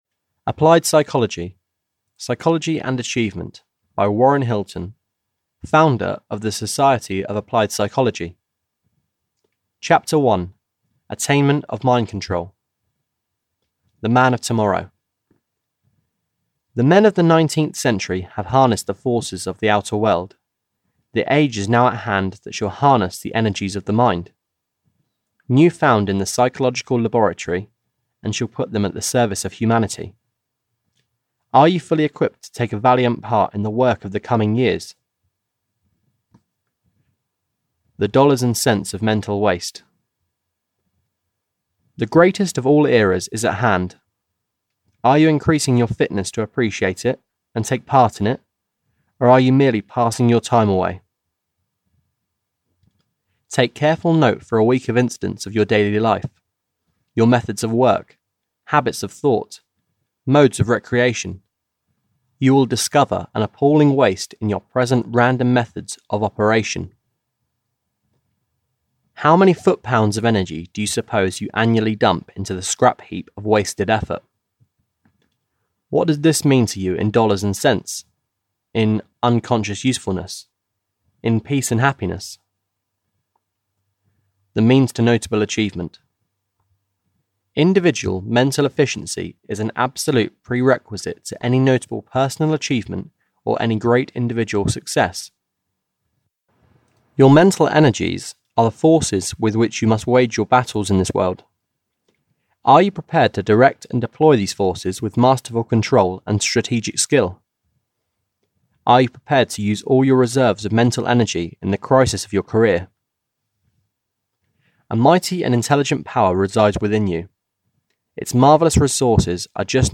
Applied Psychology (EN) audiokniha
Ukázka z knihy